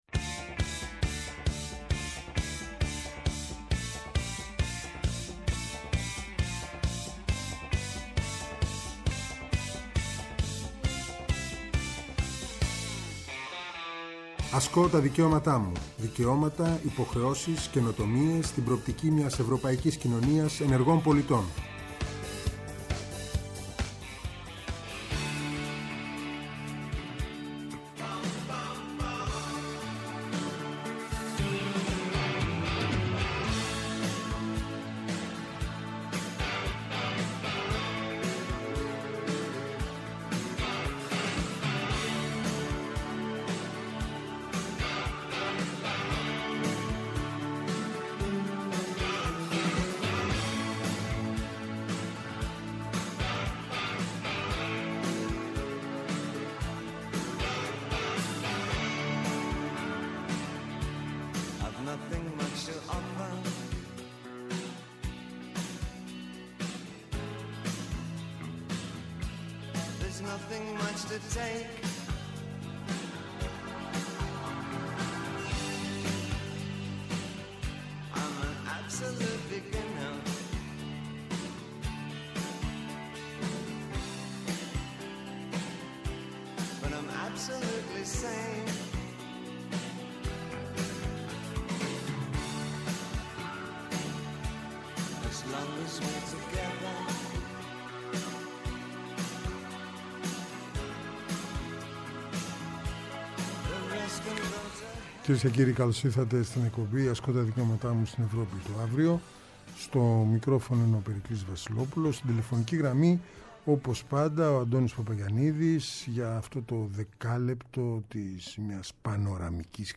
Αυτό το Σάββατο καλεσμένοι είναι :